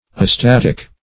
Astatic \A*stat"ic\, a. [Pref. a- not + static.]